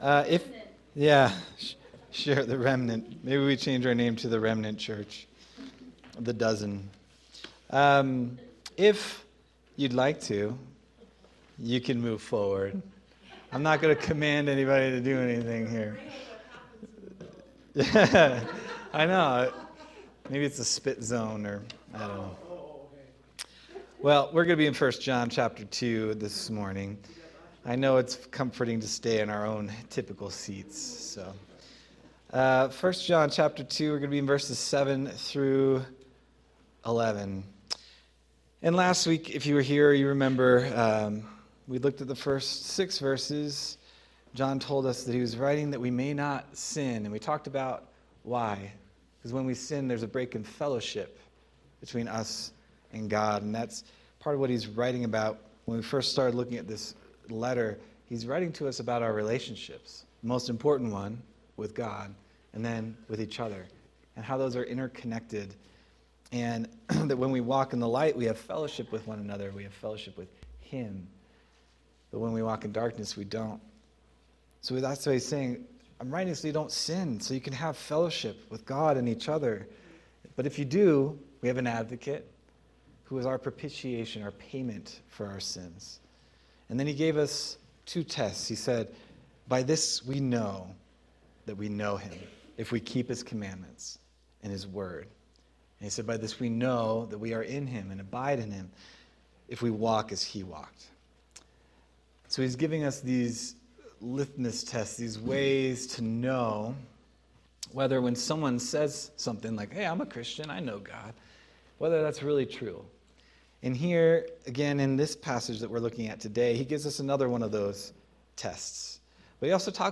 January 25th, 2026 Sermon